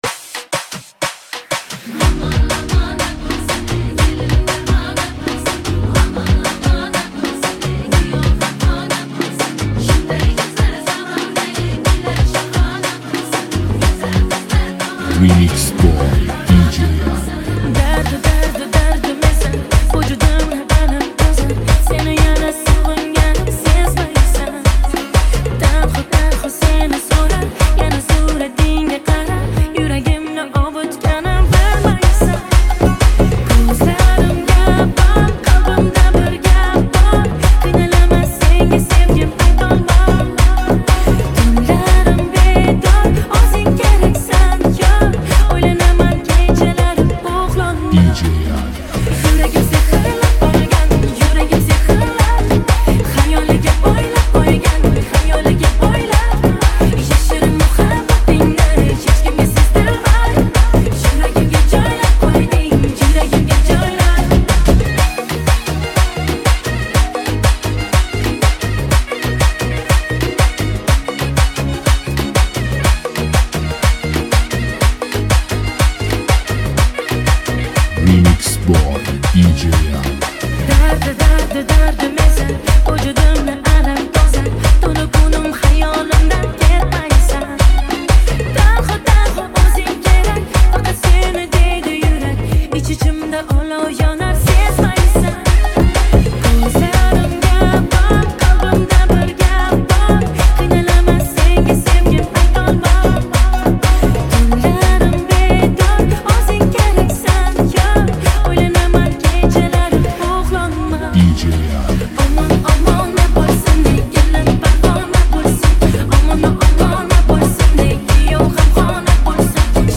موسیقی ازبکی